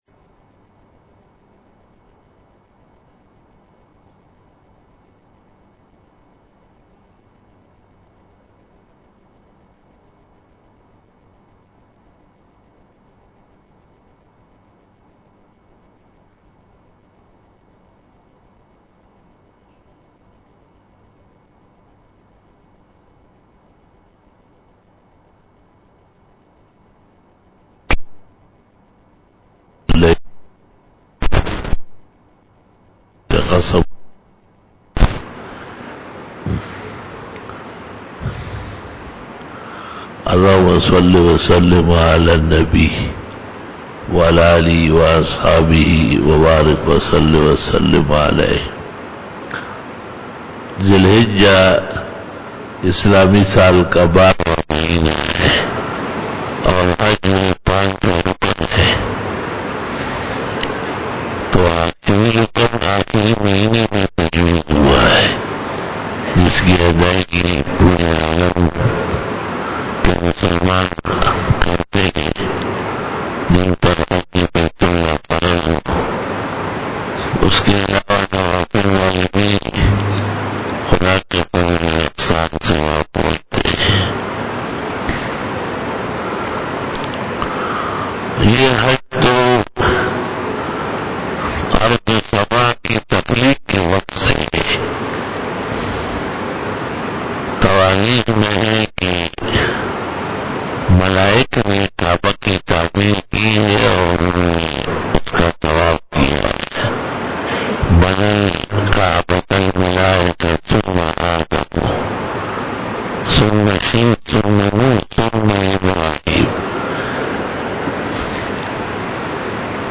31 BAYAN E JUMA TUL MUBARAK (02 August 2019) (29 Zil Qaadah 1440H)
Khitab-e-Jummah 2019